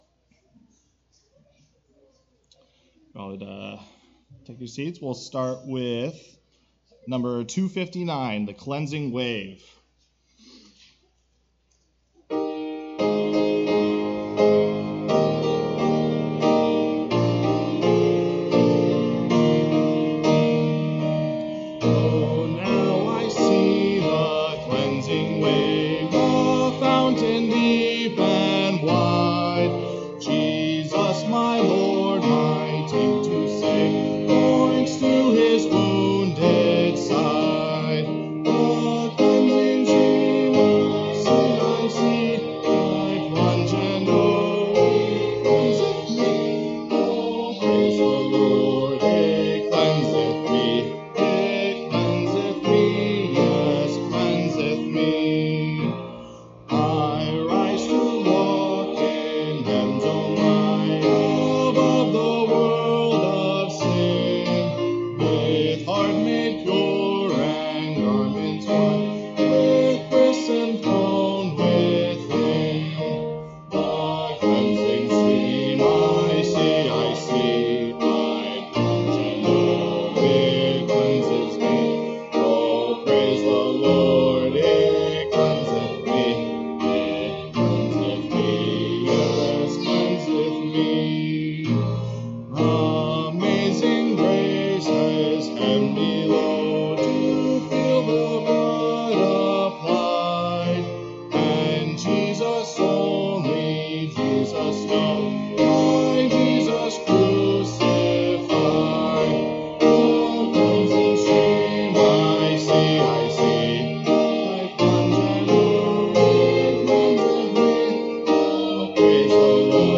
Sermons Sort By Date